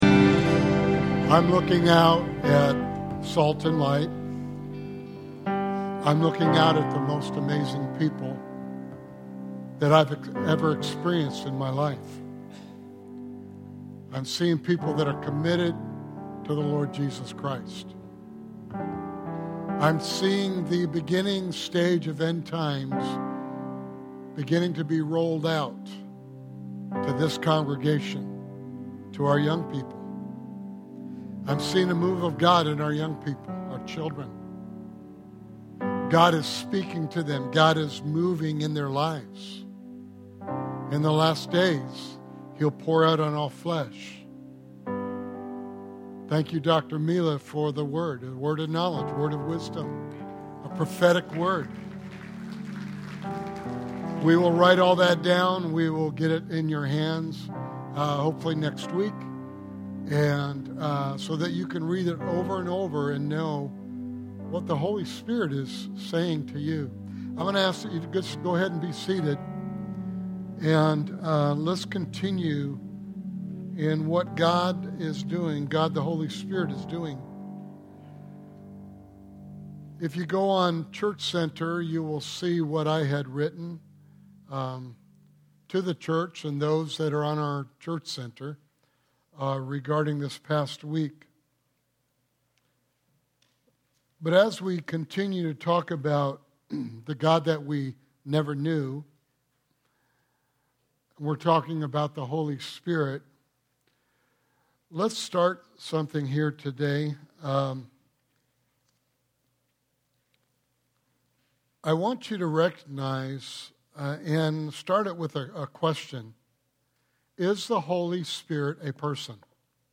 Sermon Series: The God I Never Knew